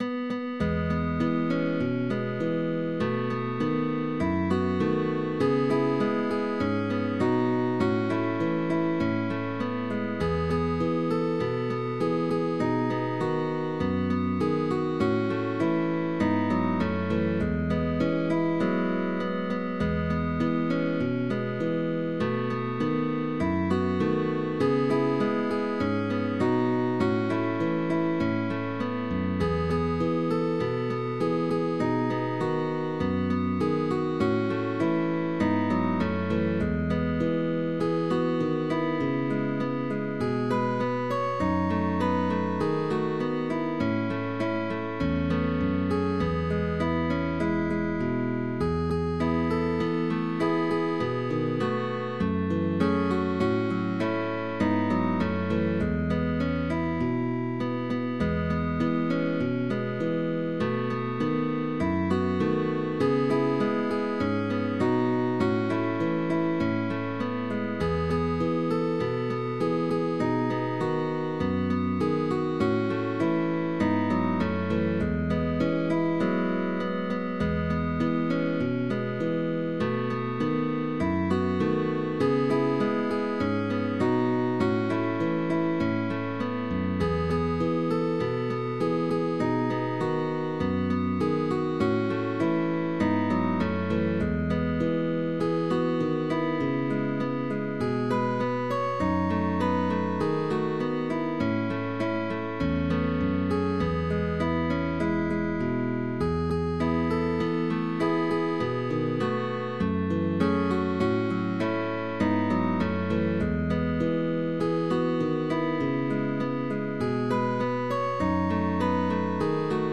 Autor: Pop music